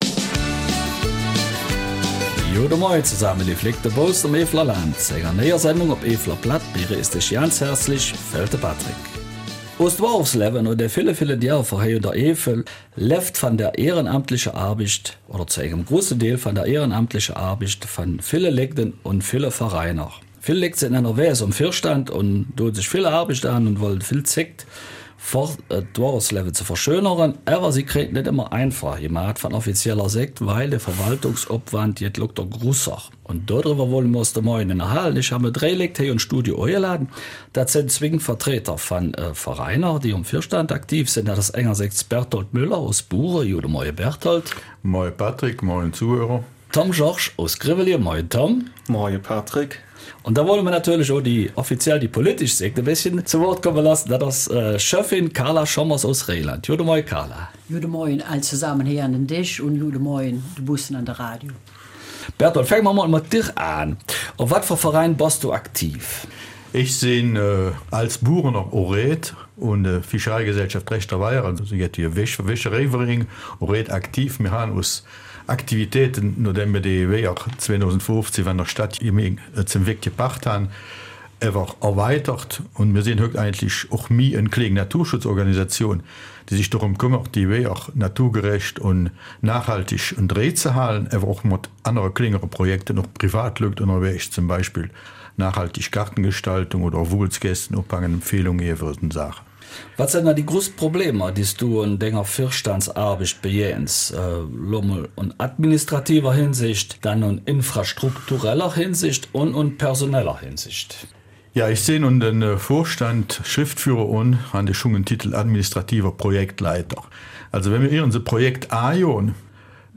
Eifeler Mundart: Ehrenamt als Verantwortlicher einer VoG